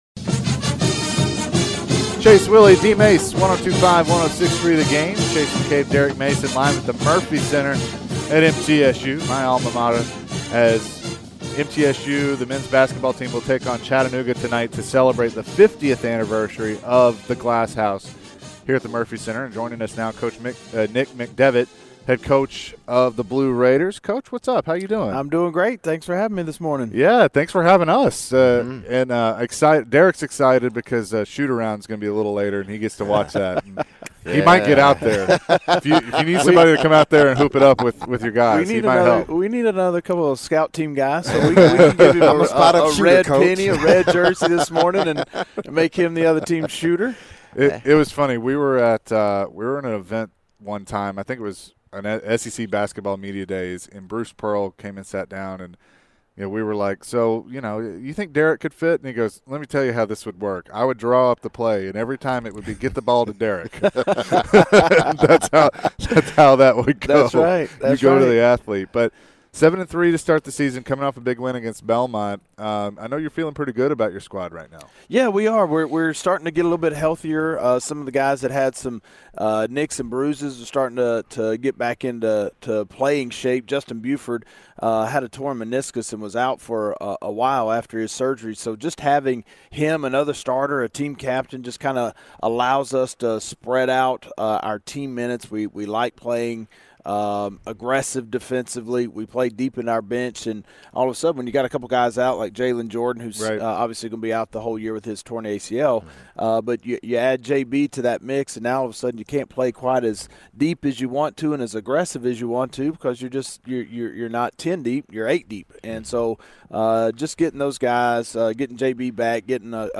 Interview
at the Murphy Center prior to their matchup against Chattanooga.